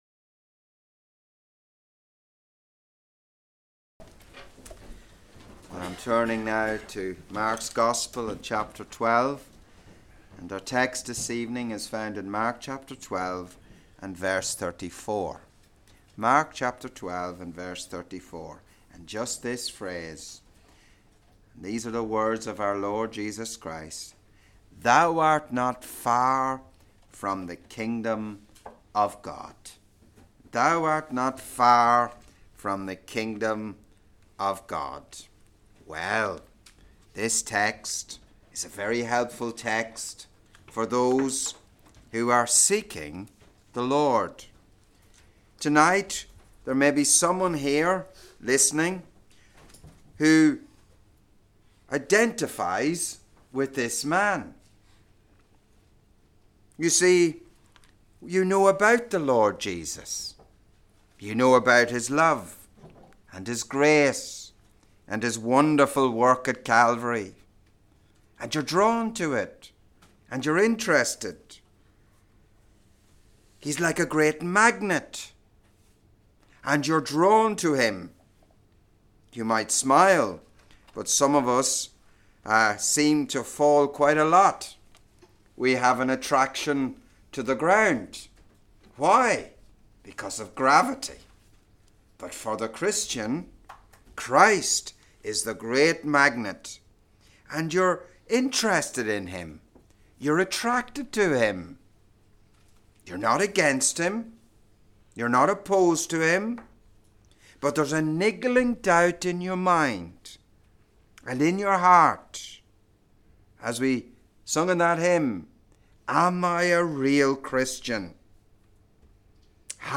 Audio includes a Brothers Baptism